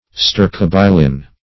Search Result for " stercobilin" : The Collaborative International Dictionary of English v.0.48: Stercobilin \Ster`co*bi"lin\, n. [L. stercus dung + E. bilin.]